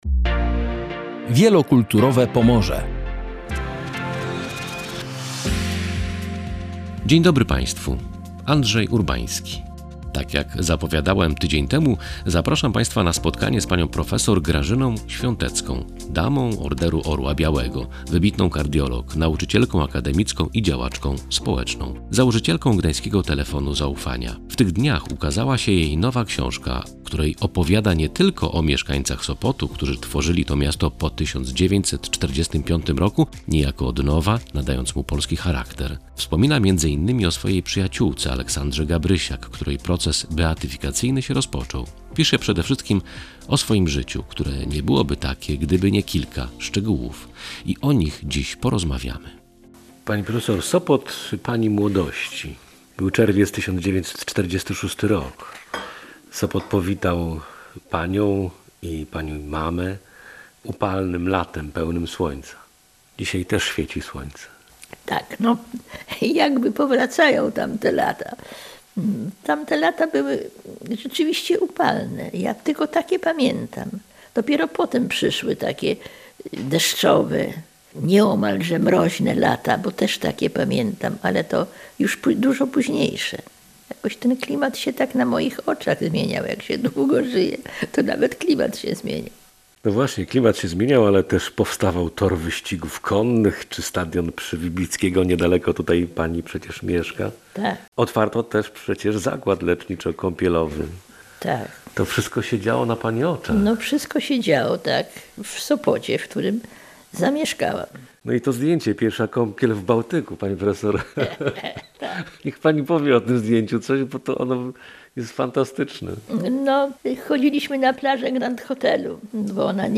I o tym jest ta rozmowa.